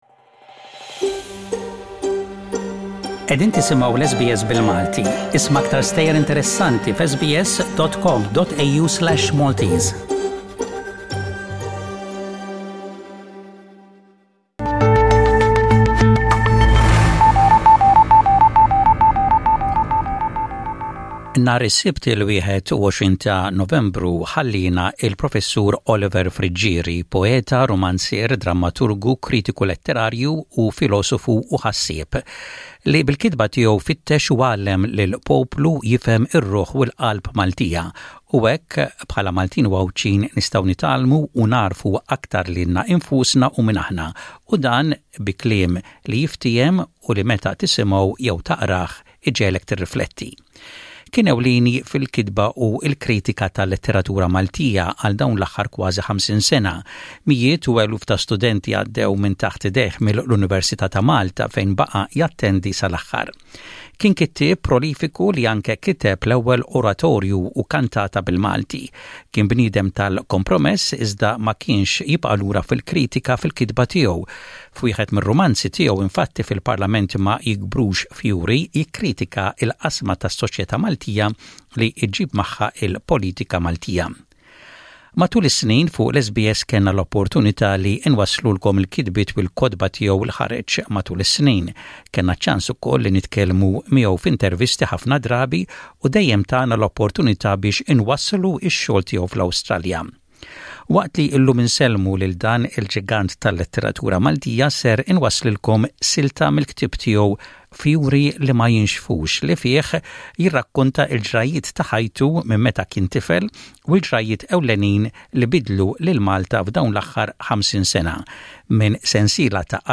Fjuri li Ma Jixfux is a memoir of his life from 1955-1990. This is an extract from his book with comments by Oliver Friggieri himself.